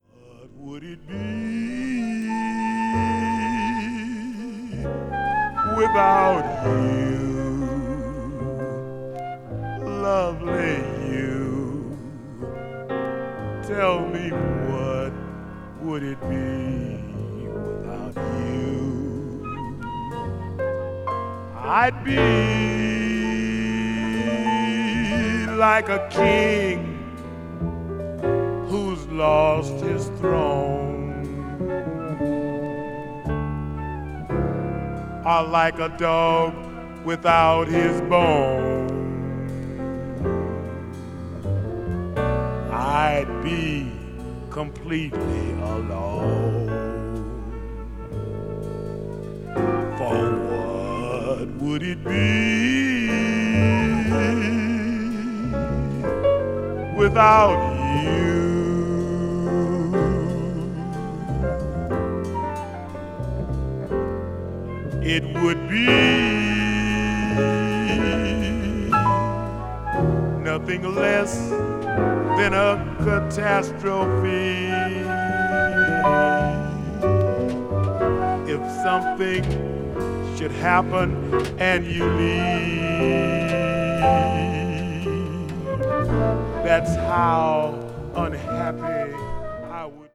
blues jazz   jazz vocal   post bop